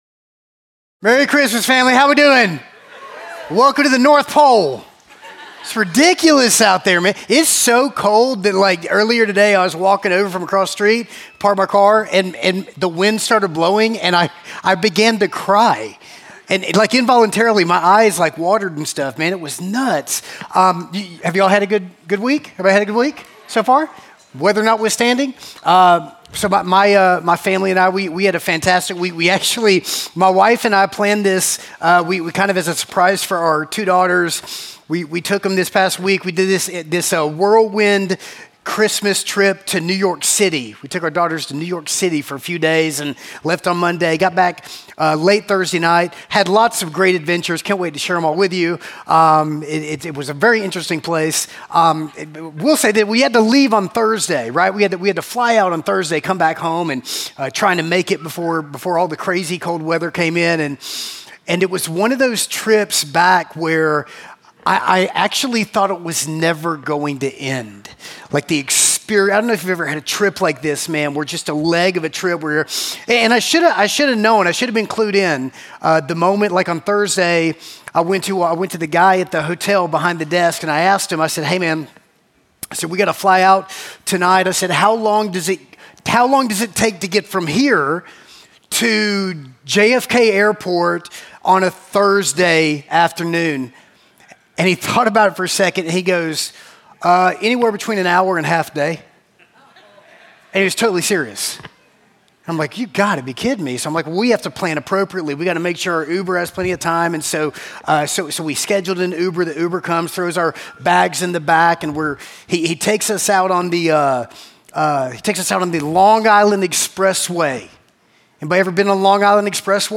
Prepare Him Room - Christmas Eve at Emmaus Church
Sermons